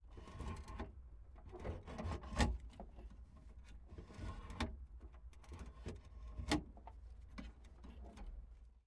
Pole Position - Messerschmitt Bf109 G4